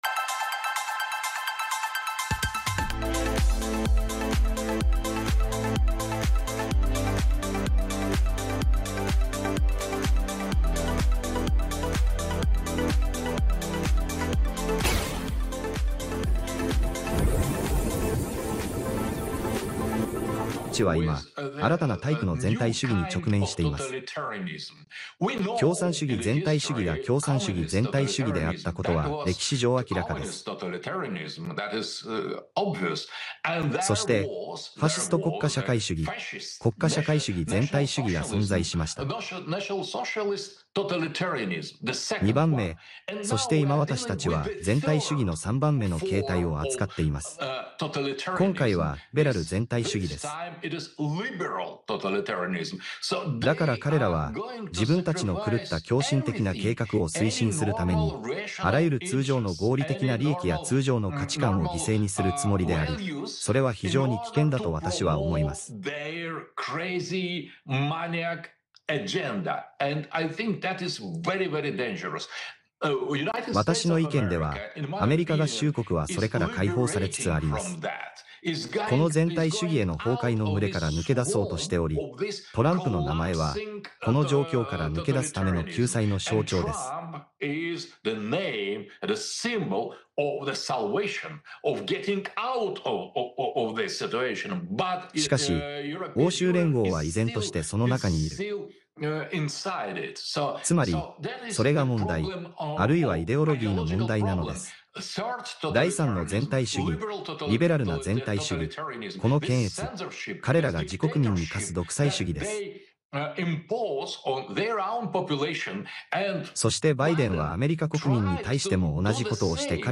「新しい全体主義：リベラル・トータリタリアニズム」がもたらす世界の危機 アレックス・ジョーンズ氏がロシアの著名な哲学者であるアレクサンドル・ドゥーギン博士を迎え、現在の世界が直面する危機的な状況、特に核戦争の脅威と、それを引き起こしているとされる「リベラル全体主義」について深く掘り下げた議論をまとめたものである。